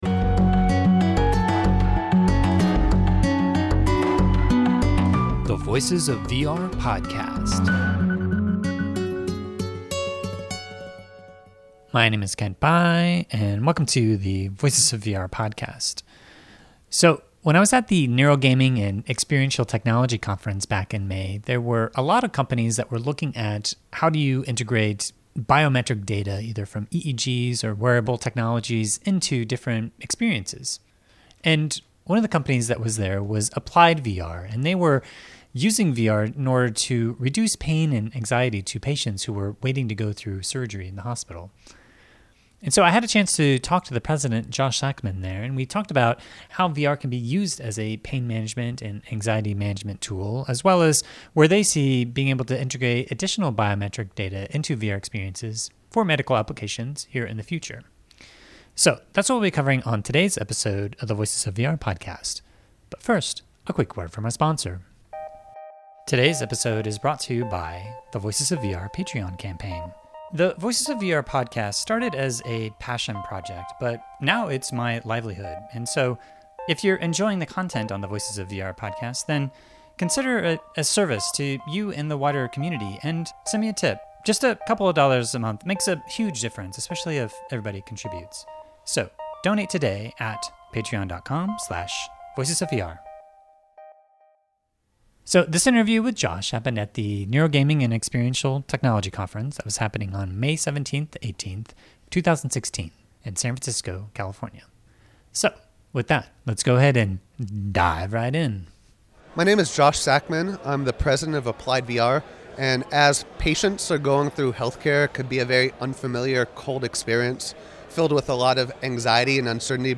This interview was conducted in May, and in June 2016 AppliedVR announced that they’re using VR technologies in the Spine Center, Department of Surgery, and Orthopaedic Center at Cedars-Sinai Medical Center in Los Angeles.